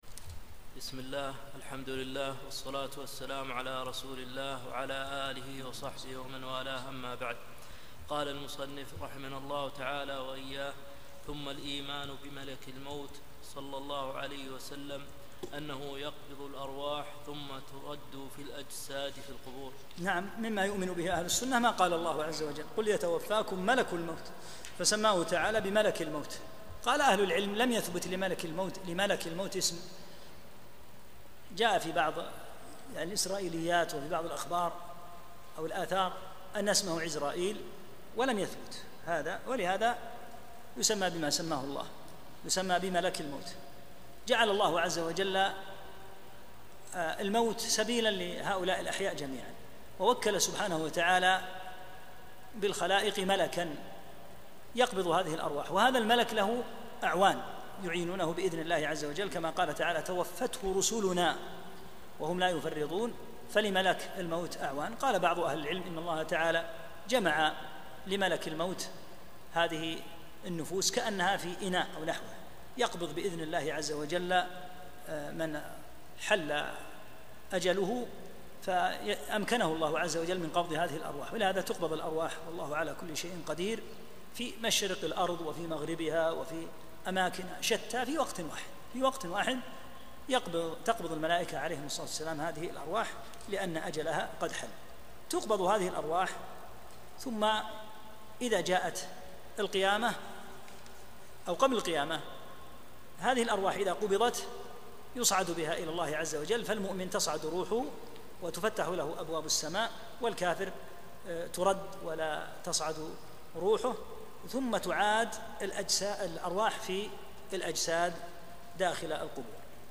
34 - الدرس الرابع والثلاثون